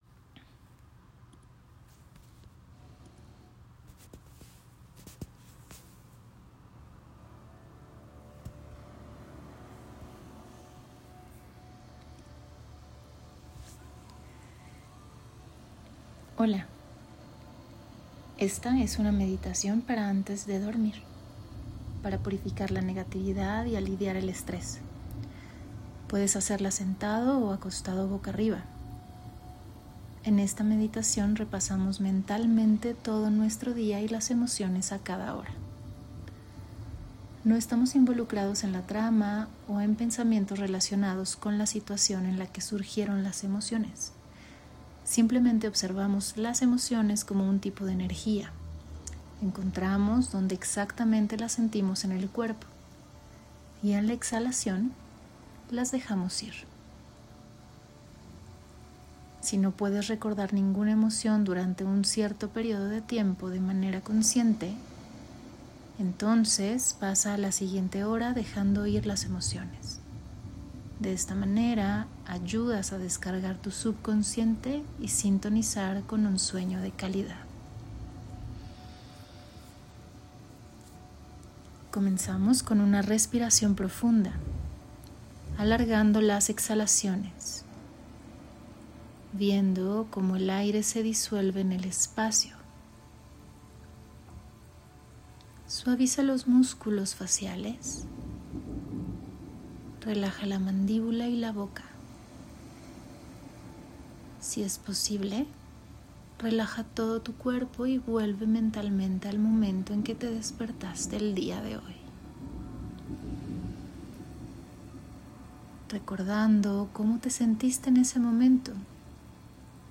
Meditación guiada para purificar la negatividad y aliviar el estrés
Esta meditación guiada está diseñada para ayudarte a conciliar el sueño de forma natural, relajando el cuerpo y llevando tu atención hacia tu estado interno.